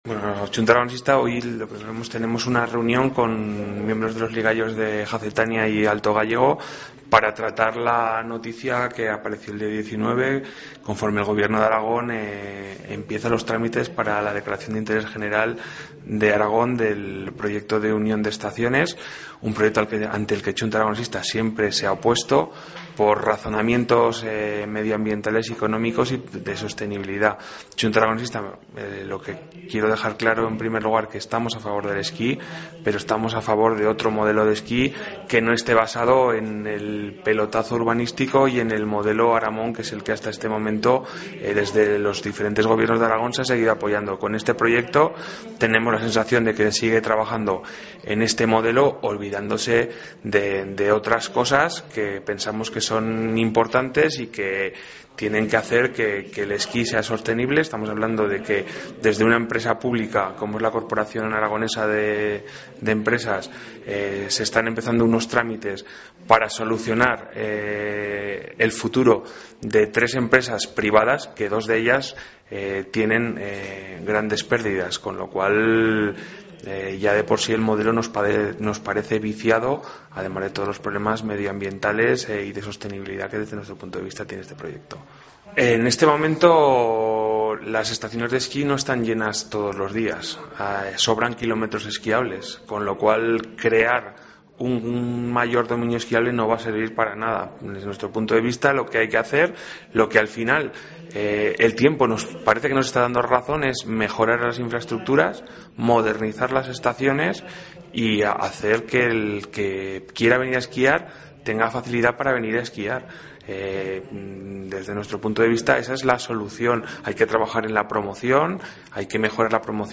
AUDIO: Declaraciones de Joaquín Palacín, diputado de Chunta en las Cortes de Aragón, sobre la unión de estaciones.